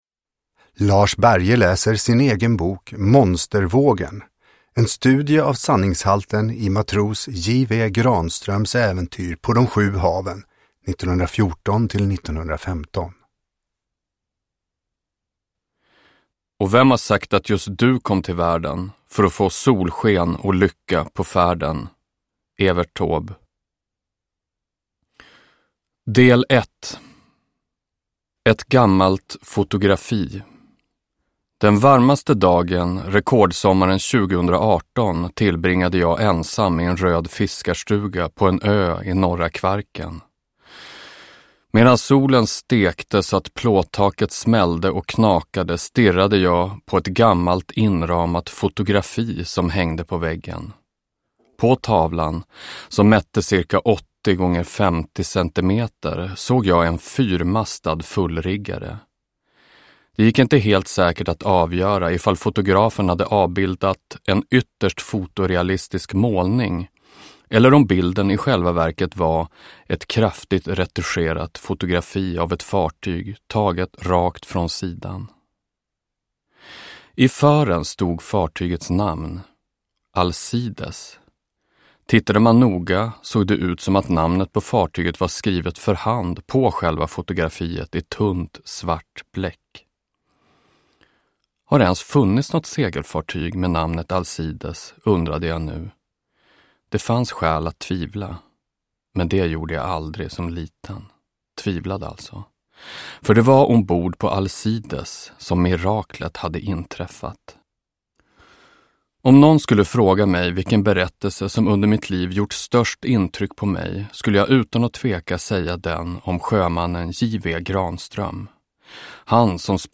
Monstervågen : en studie av sanningshalten i matros J.W. Granströms äventyr på de sju haven 1914-1915 – Ljudbok – Laddas ner